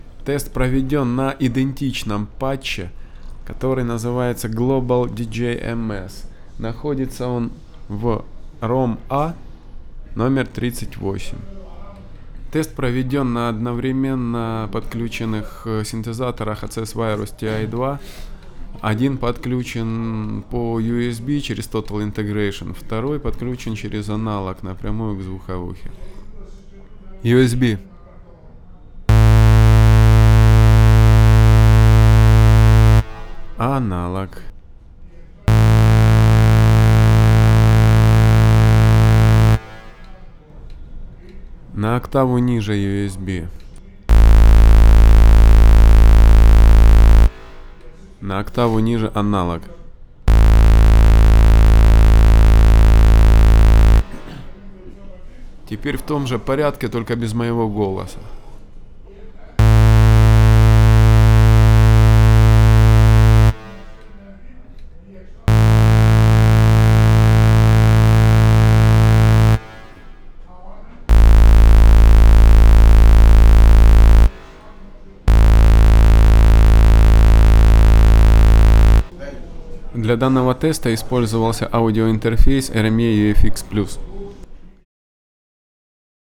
Тест сделан мной на идентичном патче,сыгранном на двух одновременно подключенных вирусах по юсб и через выходы 1-2 к звуковухе RME UFX+. Разница реально есть,слушайте. Как по мне,некоторые звуки звучат интереснее по юсб,а некоторые через аналог..тут уж дело экспериментов в вкуса!